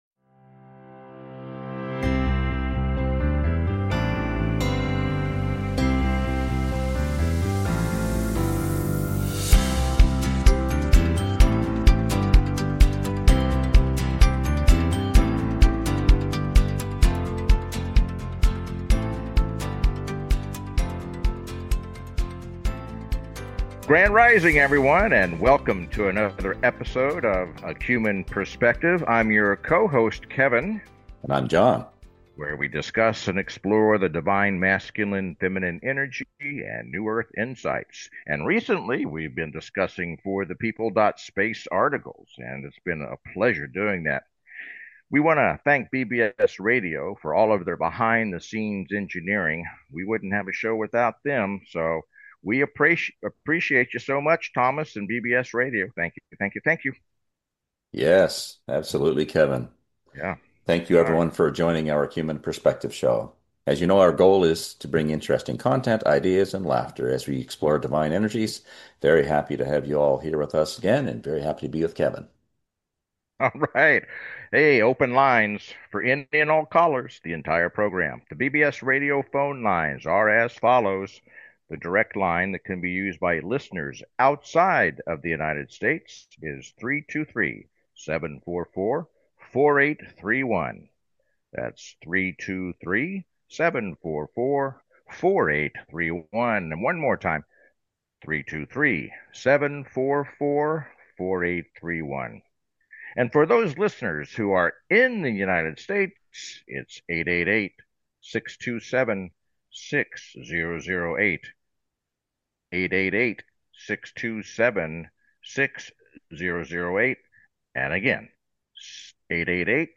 The show is structured to welcome call-ins and frequently features special guests, offering a diverse range of perspectives.
The show is not just informative but also entertaining, with humor, jokes, and a whole lot of fun being integral parts of the experience.